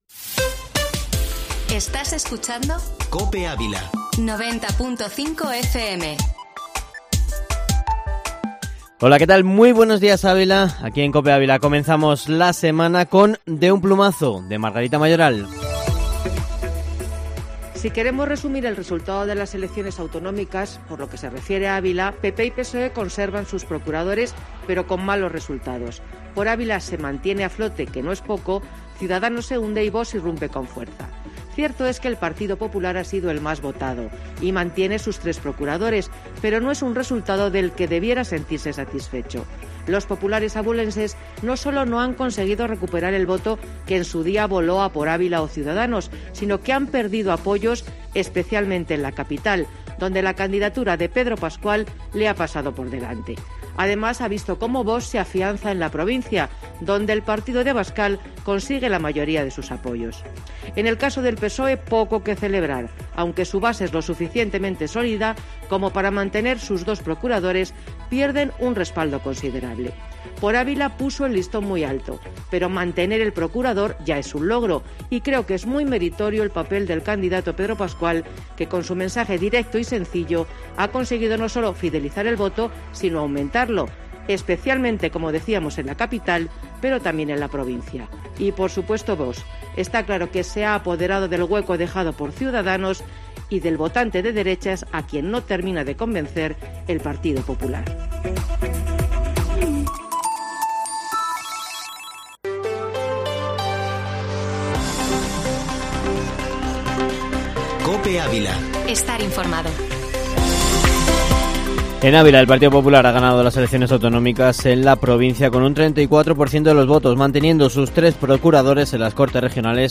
Informativo Matinal Herrera en COPE Ávila -14-febrero